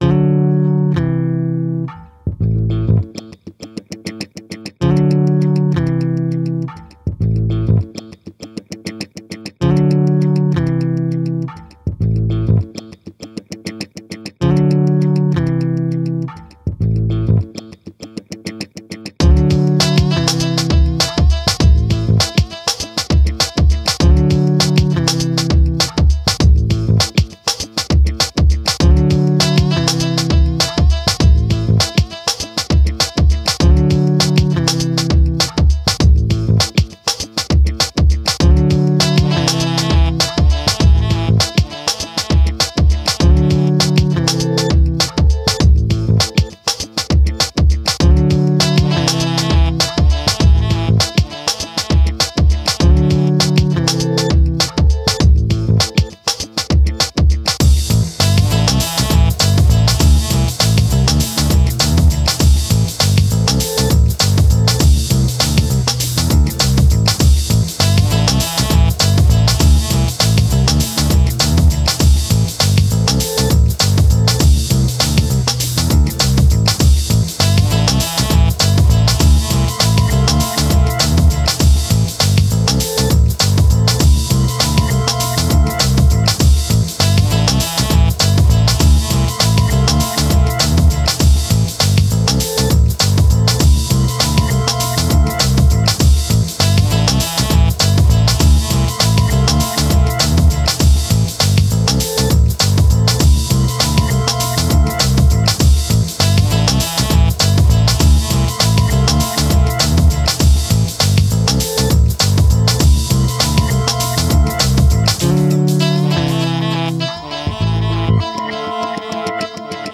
90's techno, a pinch of dub and funk and even some IDM